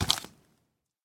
mob / zombie / step3.ogg